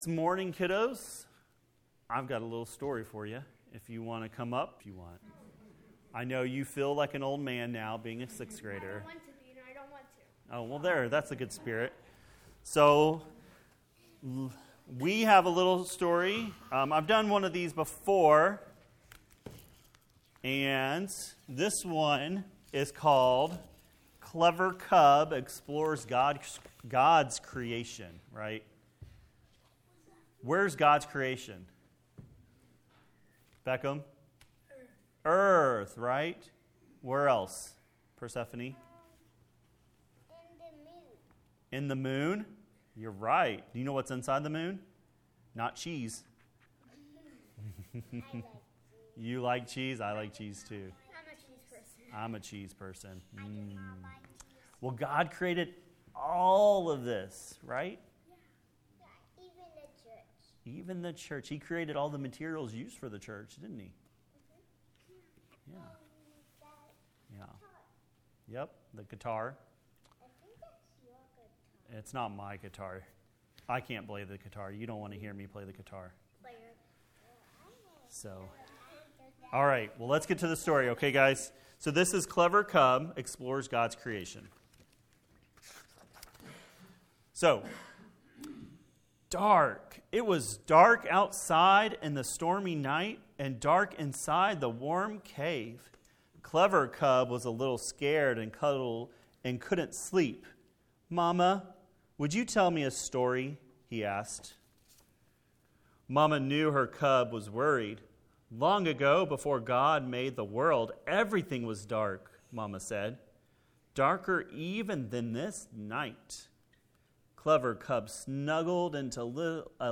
wp-content/uploads/2024/09/Creation-and-new-creation.mp3 A sermon from Genesis 1:1-31.